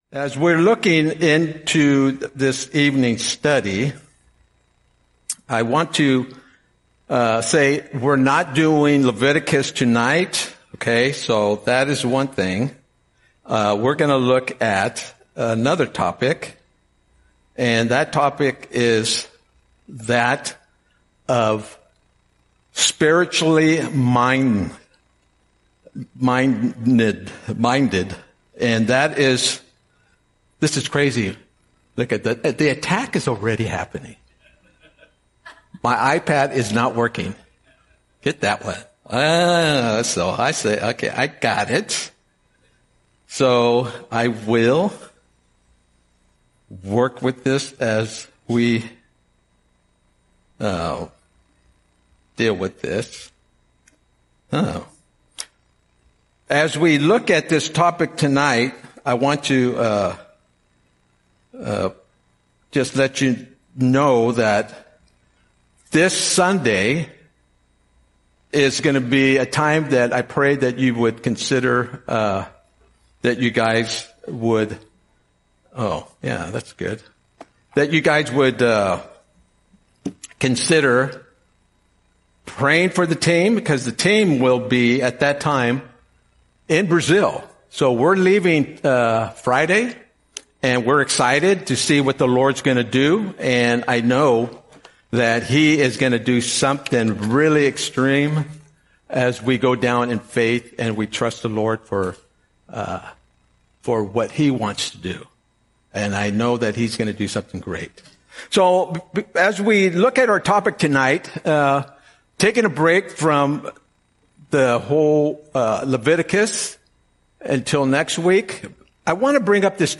Audio Sermon - October 22, 2025